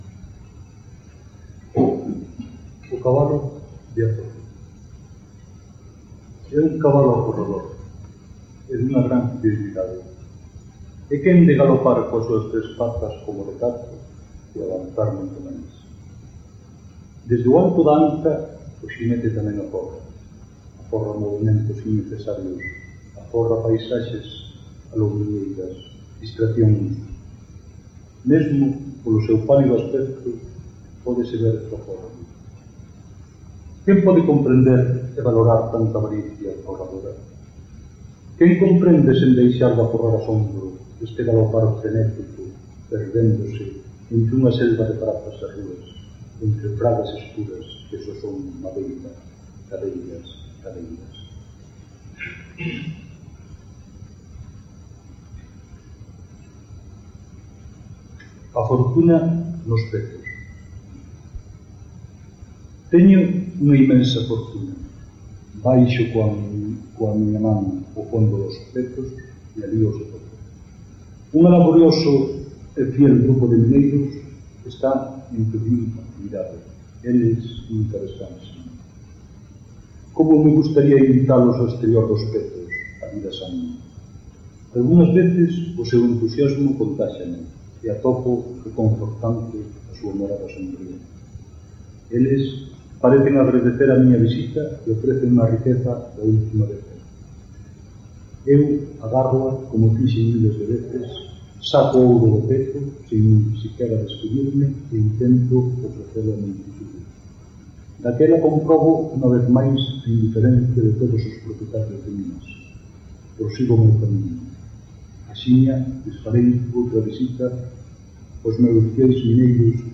Dixitalizaci�n de audio: Reverso CCL.
Gravaci�n realizada no pub Tarasca de Santiago de Compostela (r�a Entremuros, 13) o 27 de xullo de 1999. Recital organizado por Letras de Cal.